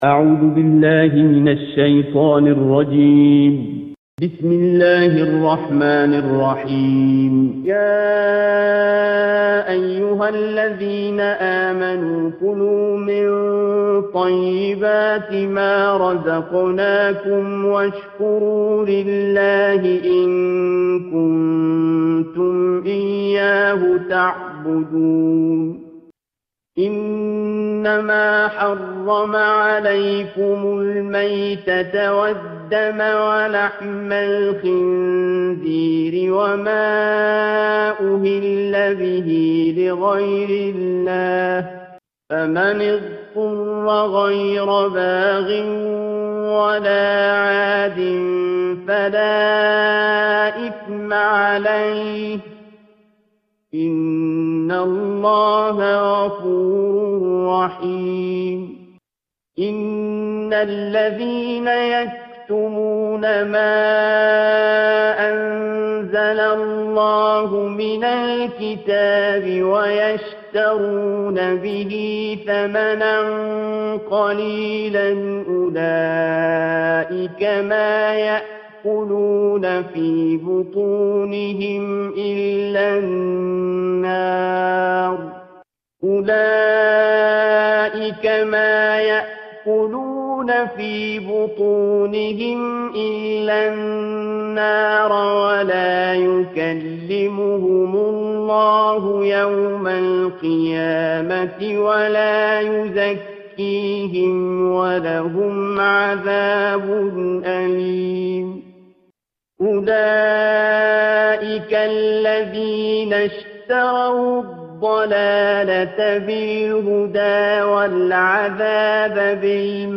Surah Al-Baqarah - A Lecture of Tafseer ul Quran Al-Bayan by Javed Ahmed Ghamidi.